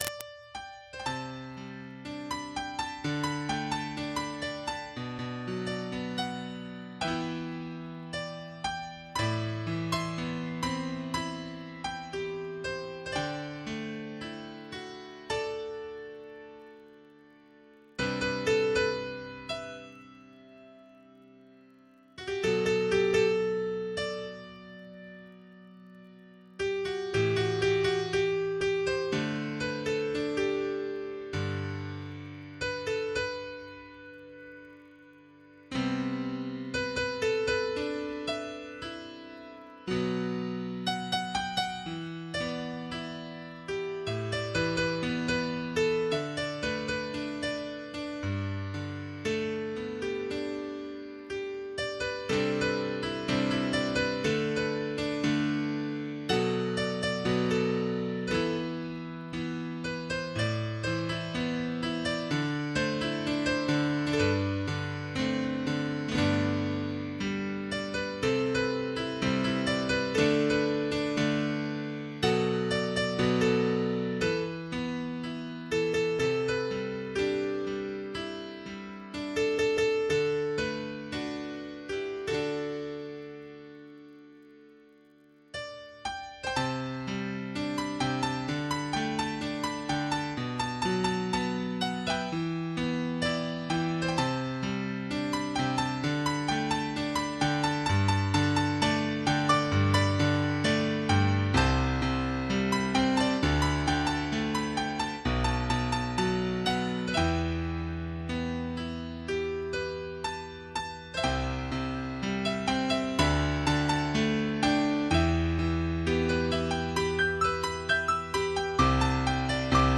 Karaoke Tracks